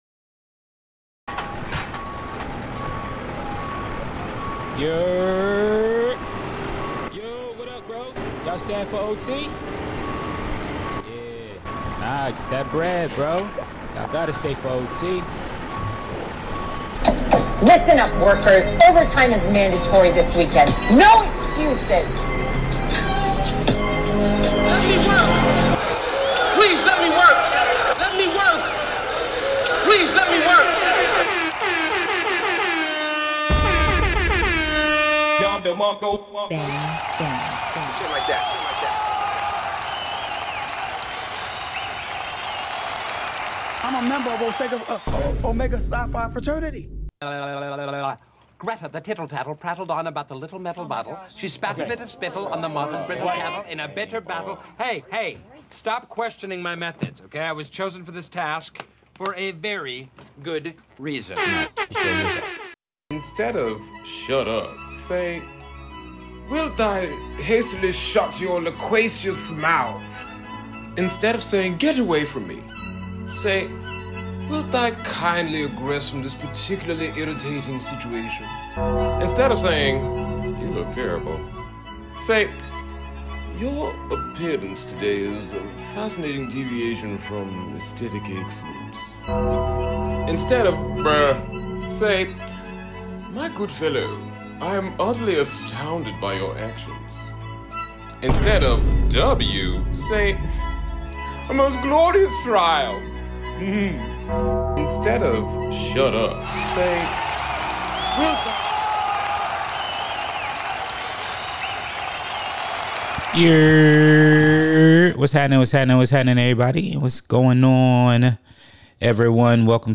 This week starts opening up with a word from our sponsors that we meant to play last week.